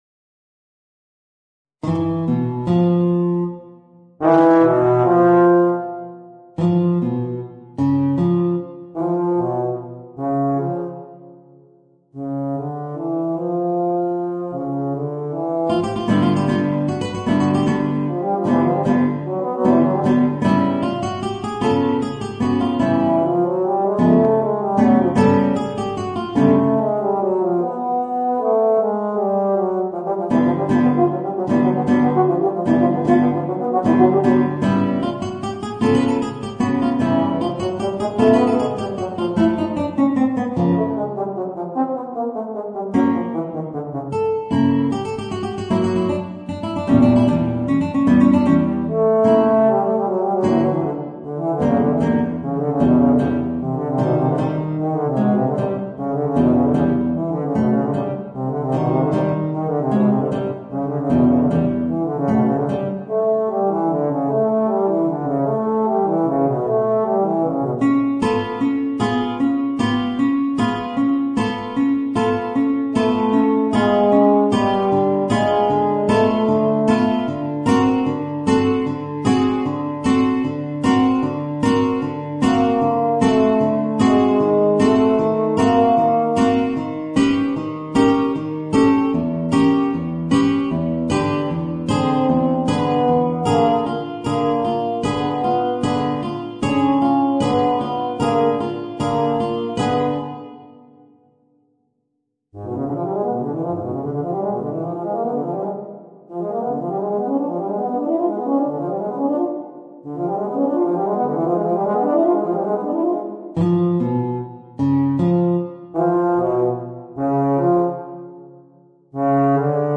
Voicing: Guitar and Euphonium